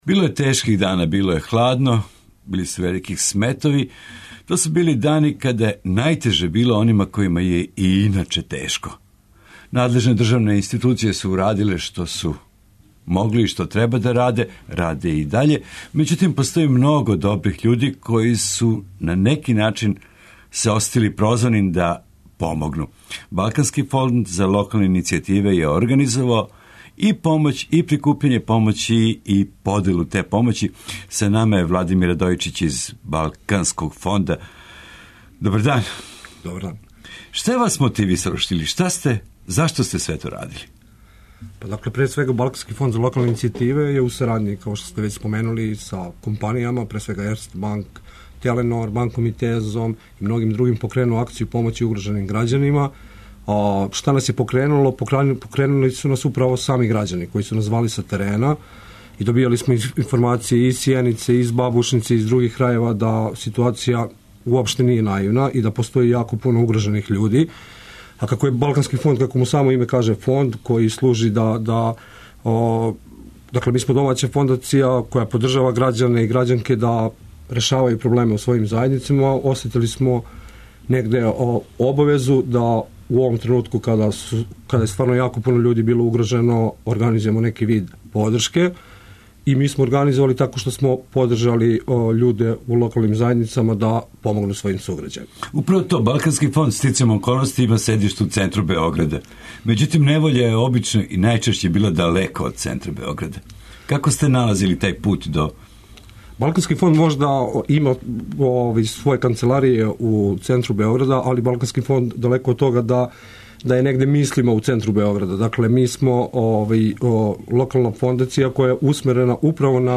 Неки од њих биће саговорници у нашој емисији